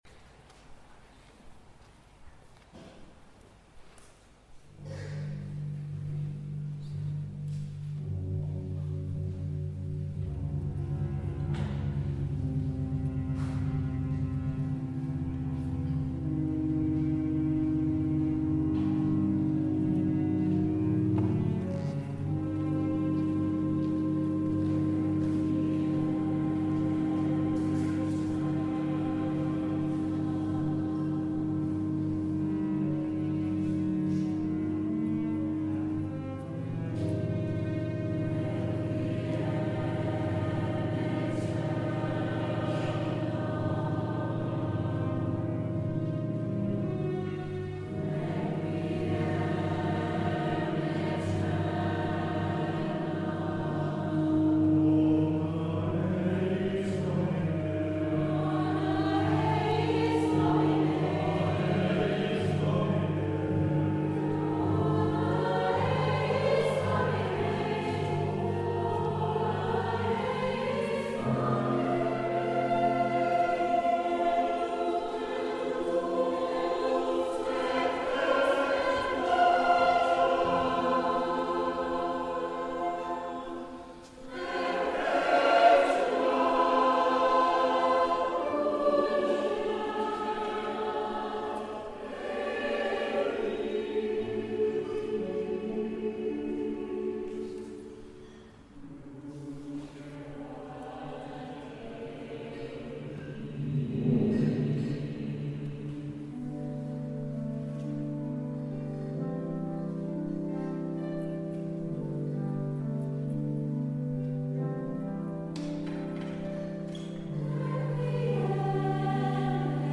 From the Requiem by John Rutter, performed at the Simon Balle Choral Concert 2017